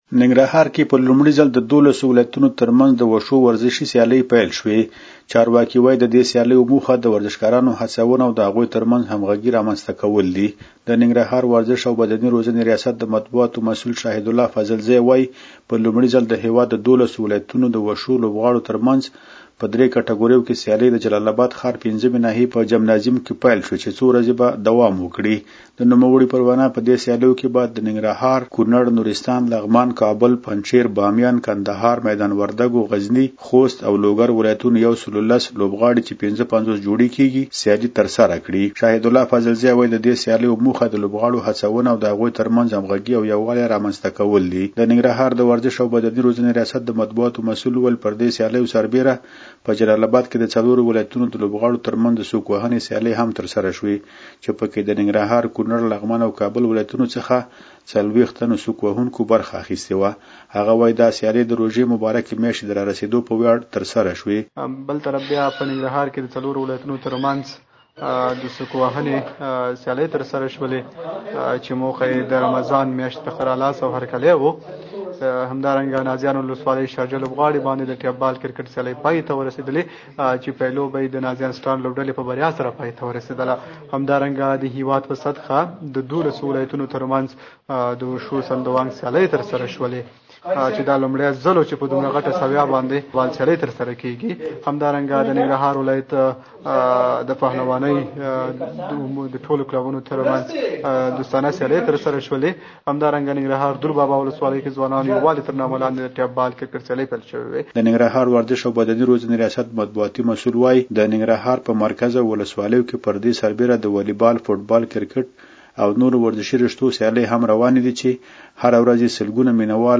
زمونږ خبریال راپور راکړی ننګرهار کې په لومړي ځل د دولسو ولایتونو تر منځ د وشو ورزشي سیالۍ پېل شوې.